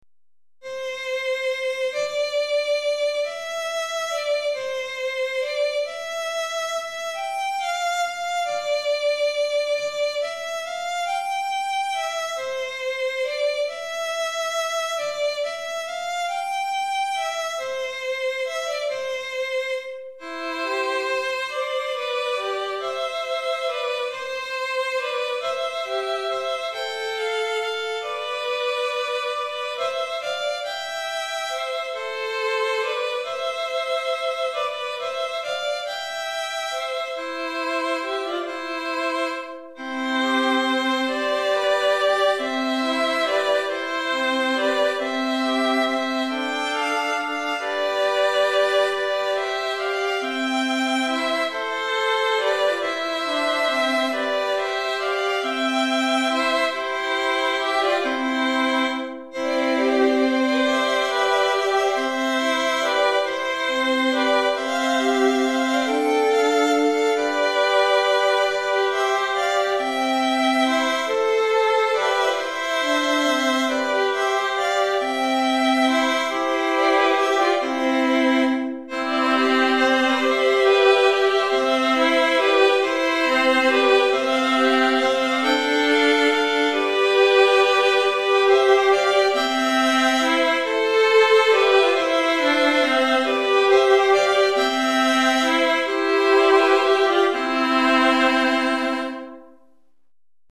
3 Violons